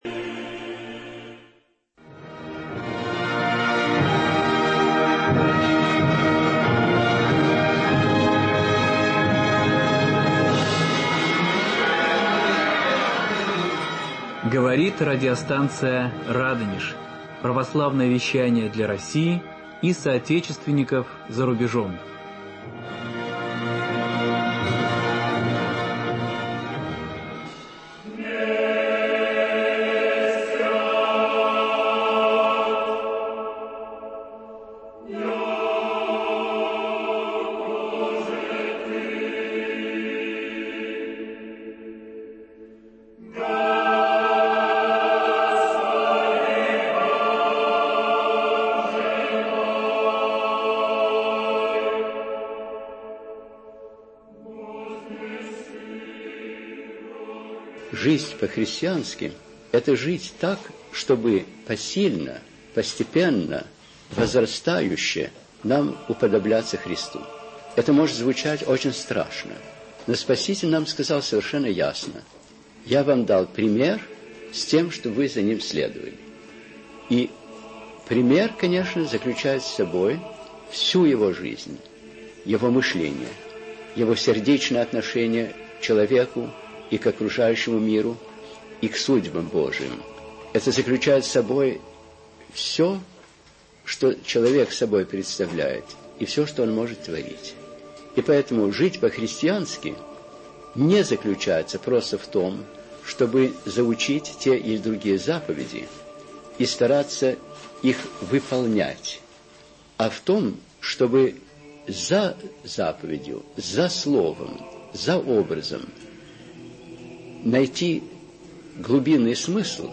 Слушать Скачать MP3-архив часа Сегодня в студии мы встречаемся с разными людьми, которые причастны к выходу в свет нового, третьего тома трудов Владыки Антония (Блума), осуществленного усердием и старанием Фонда духовного наследия митрополита Антония Сурожского.